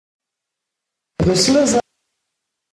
prononciation Brusseles, hein prononciation Brusseles exemple Mo da's geen echte Brusseles, he